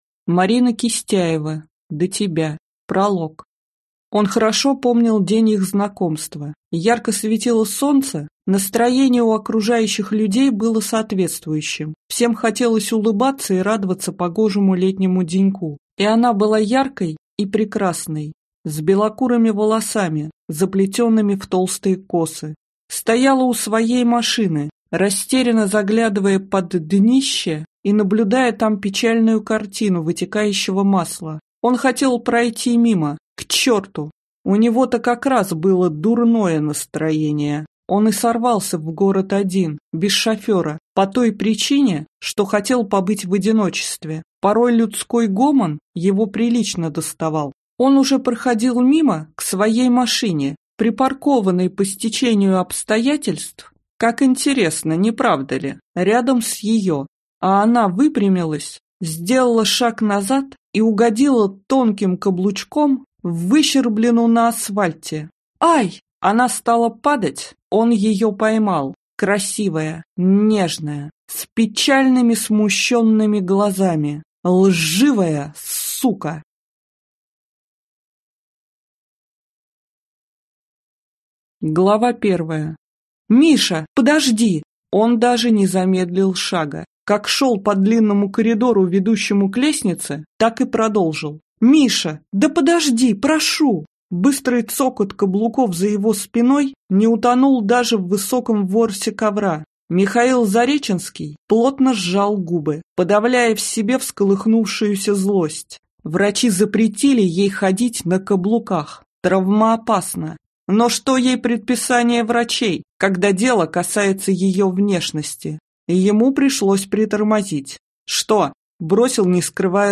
Аудиокнига До тебя | Библиотека аудиокниг